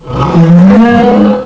-Replaced the Gen. 1 to 3 cries with BW2 rips.
bouffalant.aif